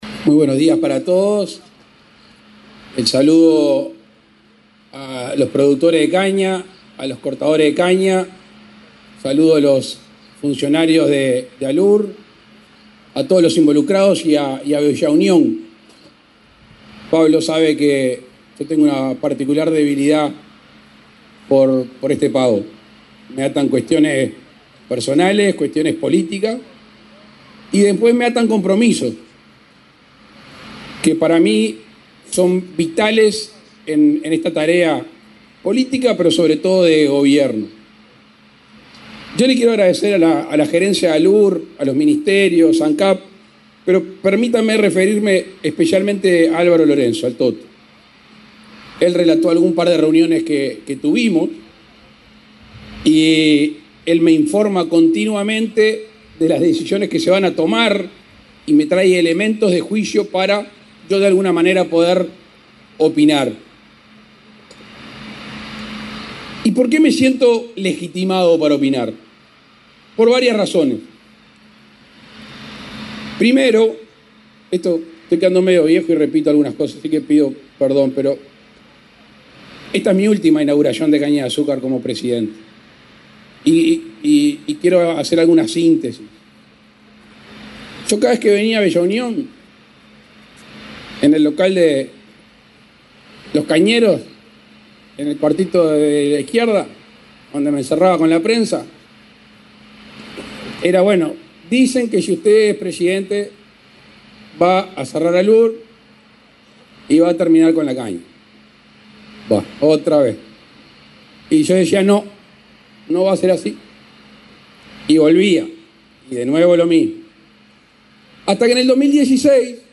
El presidente Luis Lacalle Pou, fue el orador central, este jueves 9 en la planta de Alur en Bella Unión, en Artigas, del acto de inicio de la Zafra